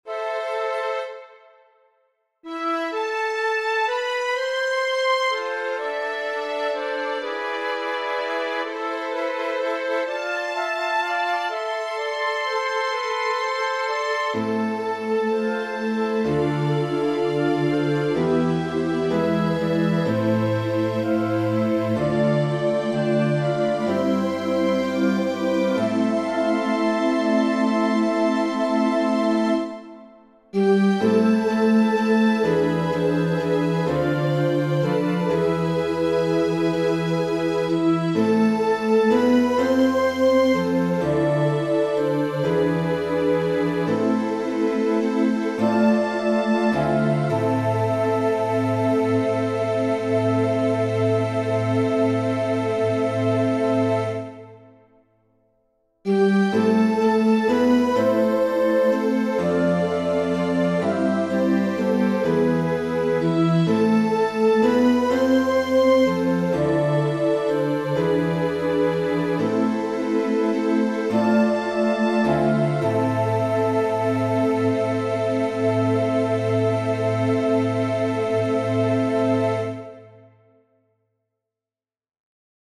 • Catégorie : Chants de Sanctus
Stats:     186      174 Tags: Sanctus